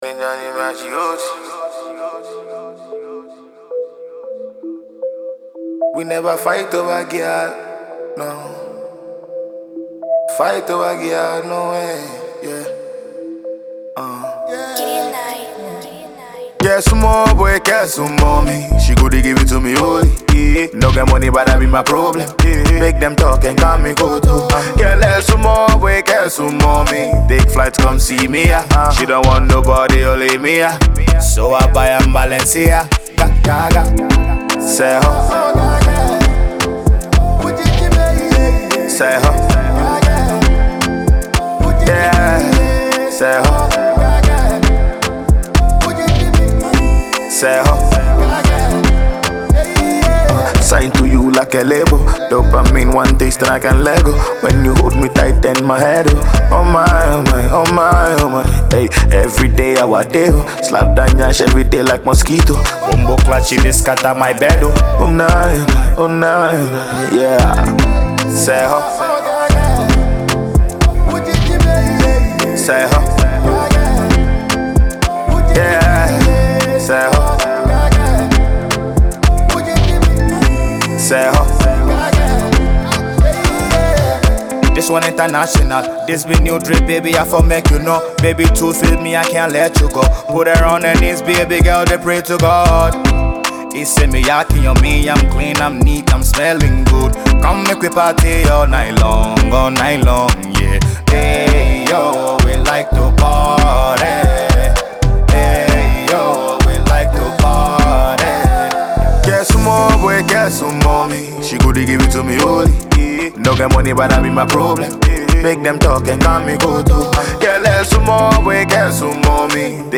a Ghanaian artist
studio tune